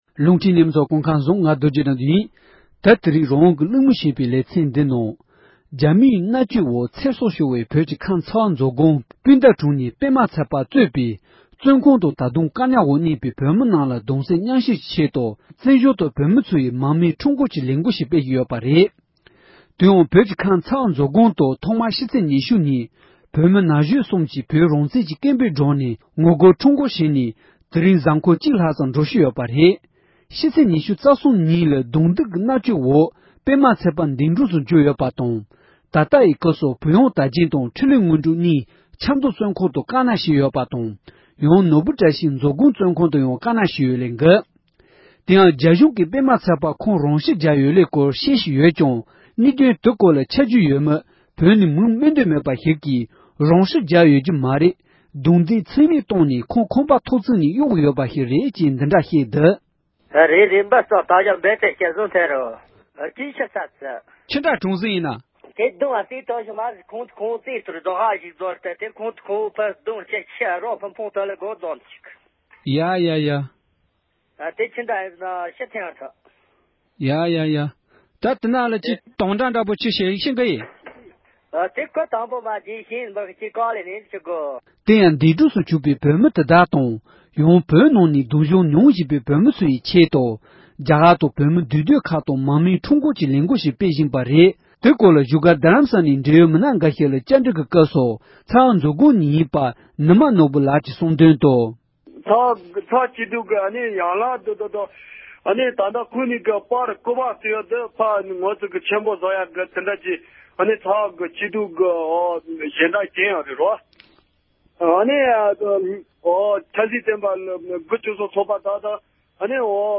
འབྲེལ་ཡོད་མི་སྣ་ཁག་ལ་བཀའ་འདྲི་ཞུས་པ་ཞིག་གསན་རོགས་གནང་༎